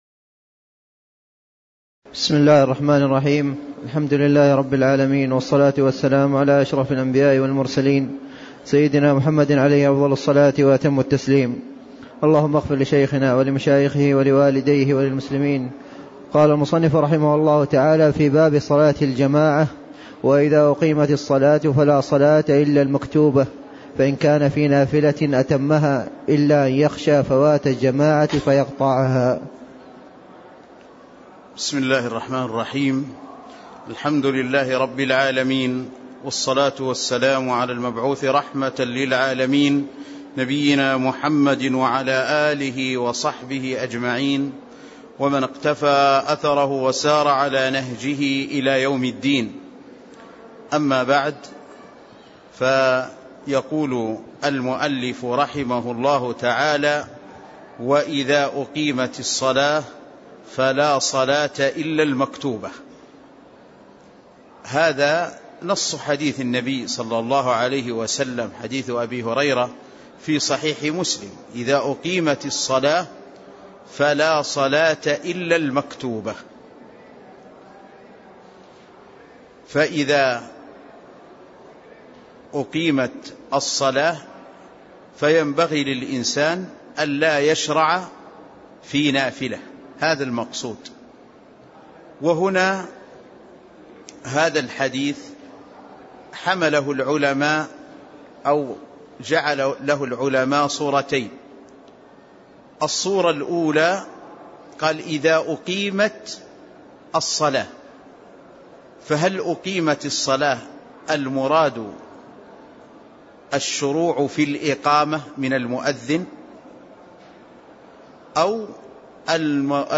تاريخ النشر ٣ صفر ١٤٣٦ هـ المكان: المسجد النبوي الشيخ